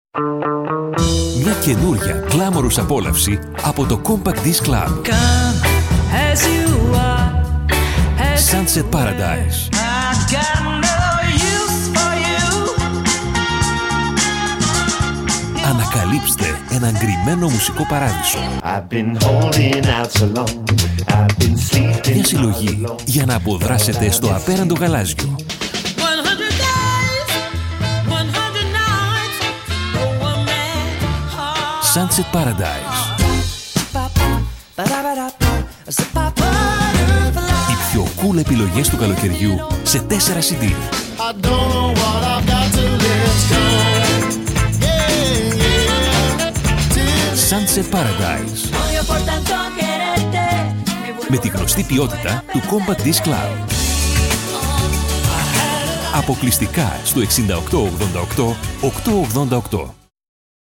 Sprechprobe: Sonstiges (Muttersprache):
The amazing Greek voice